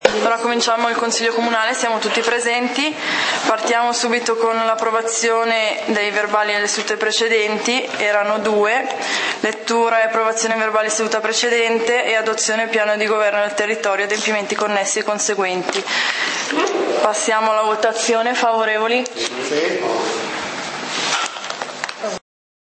Consiglio comunale di Valdidentro del 30 Aprile 2014